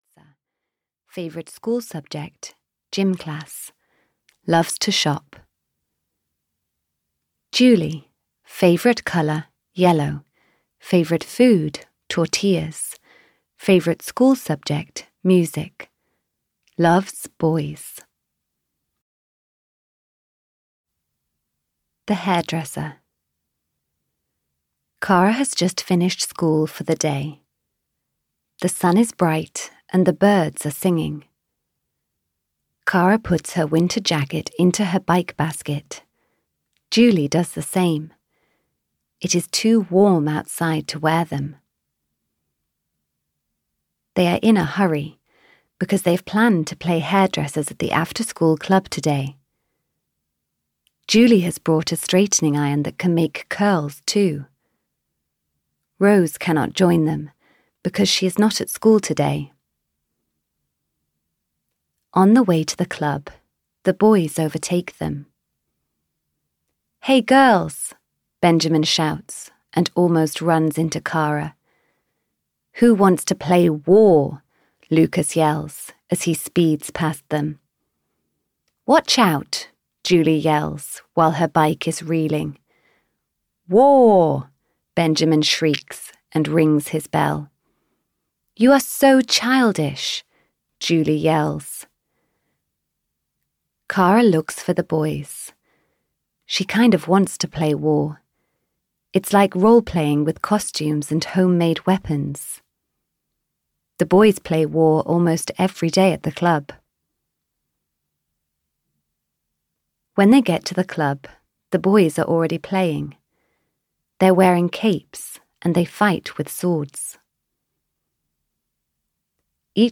K for Kara 6-10. Playdates and Promises (EN) audiokniha
Ukázka z knihy